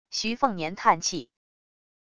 徐凤年叹气wav音频
徐凤年叹气wav音频生成系统WAV Audio Player